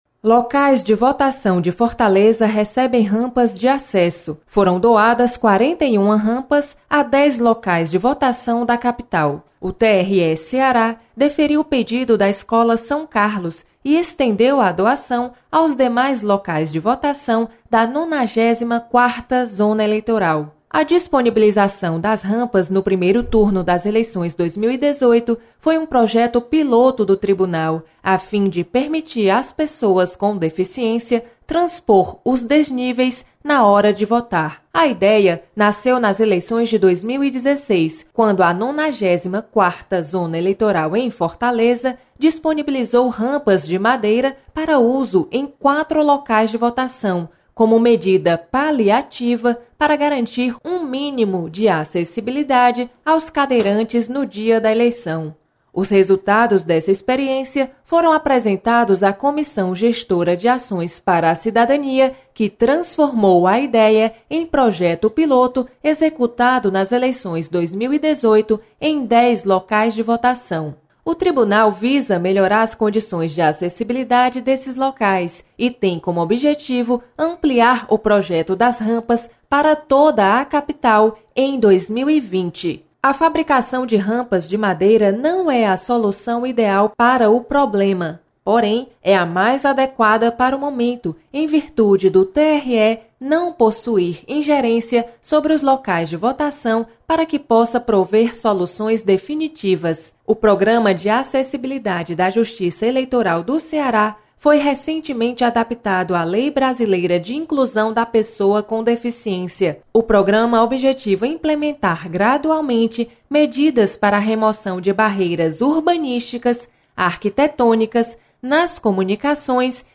Acessibilidade recebe atenção do TRE Ceará. Repórter